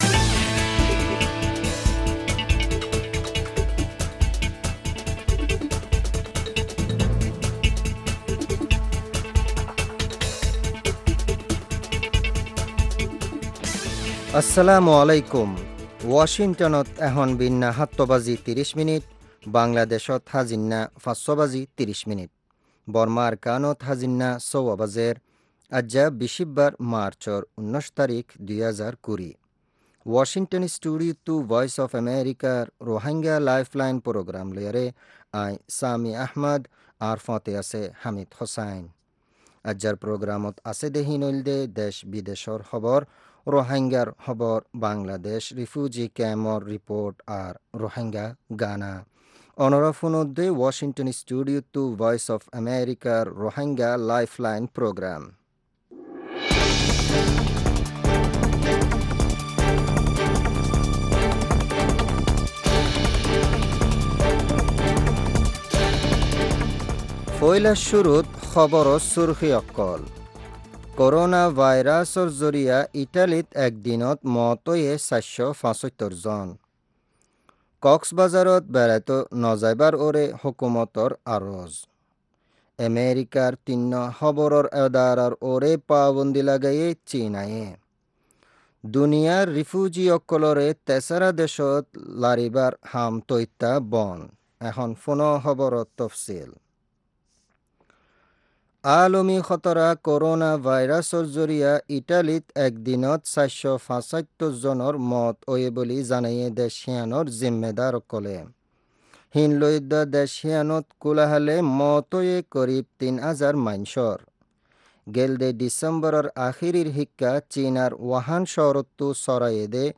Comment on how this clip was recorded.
Shortwave, 31-meter band 9310 kHz 25-meter band, 11570 kHz, 12030 kHz